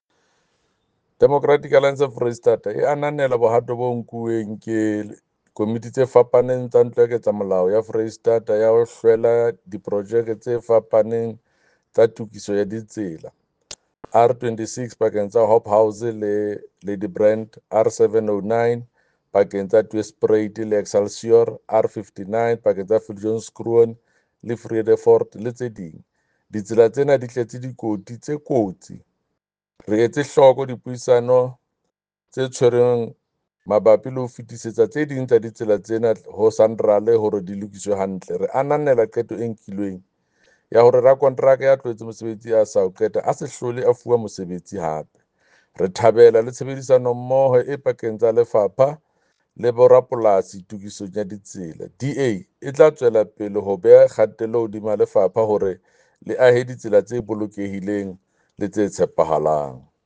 Sesotho soundbites by Jafta Mokoena MPL with pictures here, and here